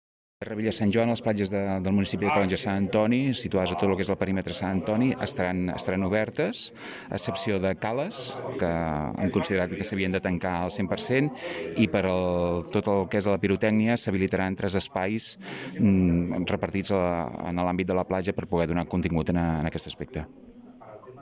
Així ho explica a Ràdio Capital el regidor de Turisme i Platges de l’Ajuntament de Calonge i Sant Antoni, Joan Caner.